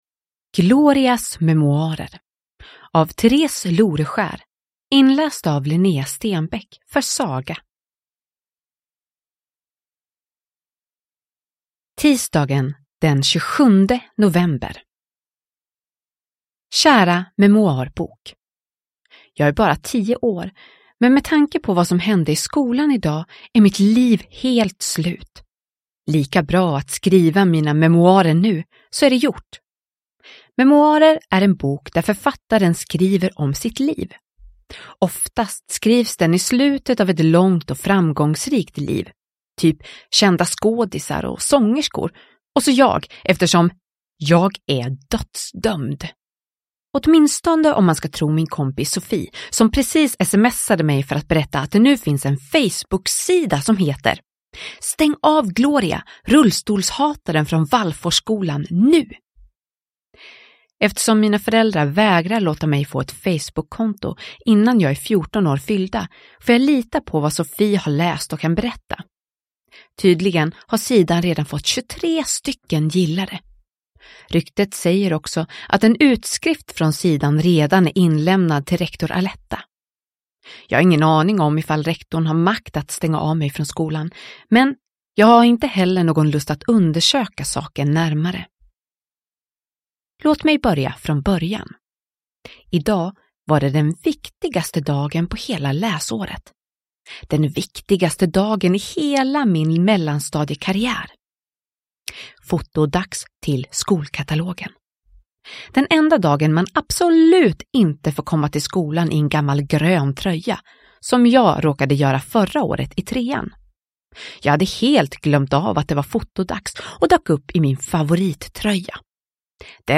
Glorias memoarer – Ljudbok – Laddas ner